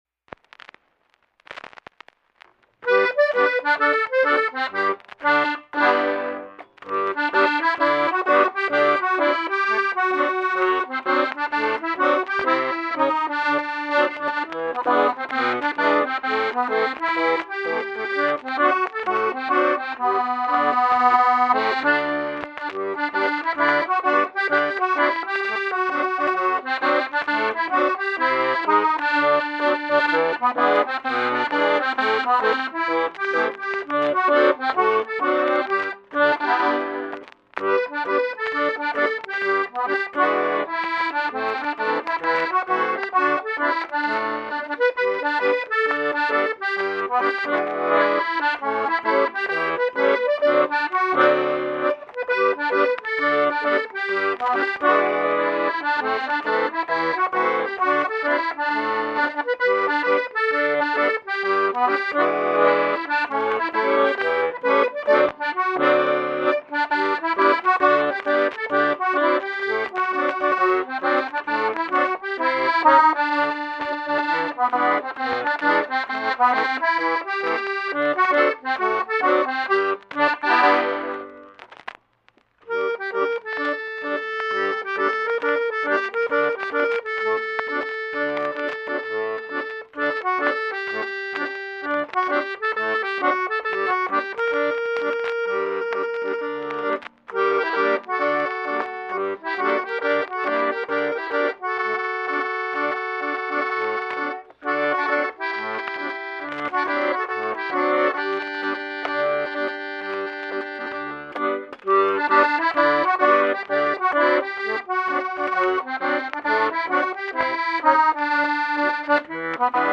甜美哀伤